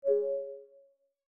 MRTK_Notification.wav